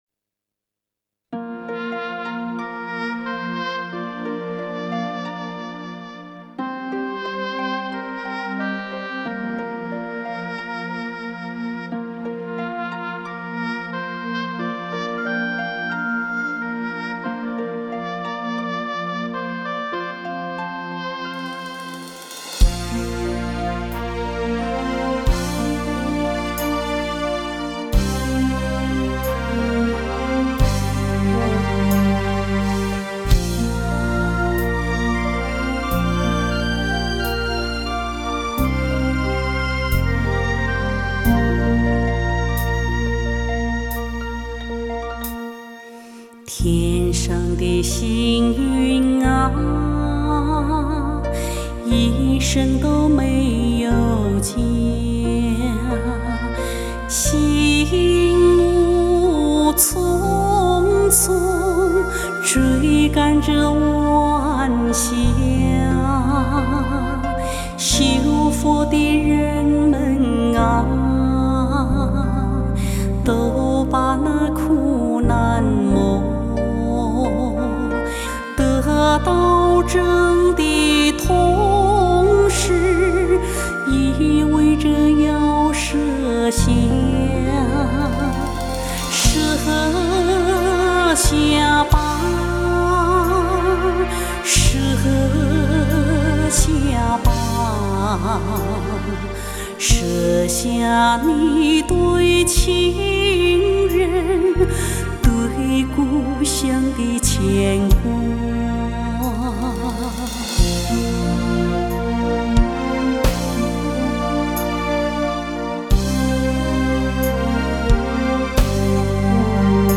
佛歌